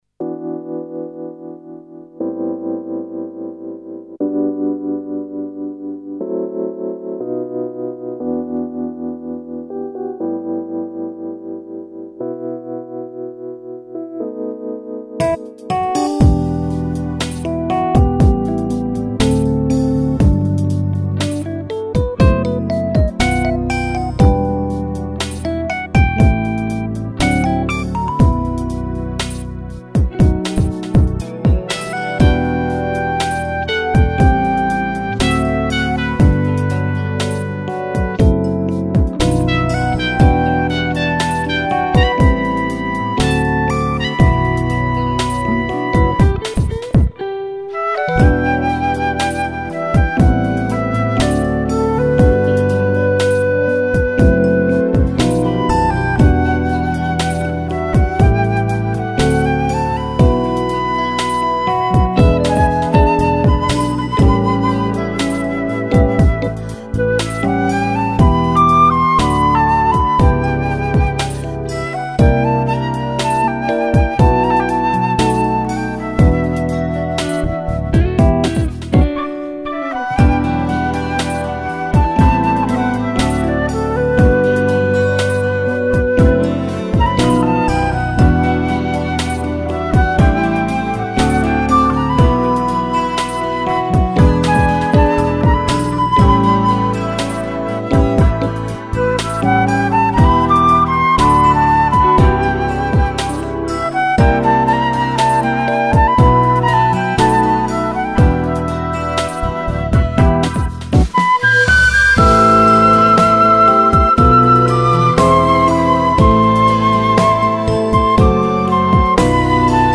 音樂類型：純音樂